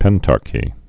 (pĕntärkē)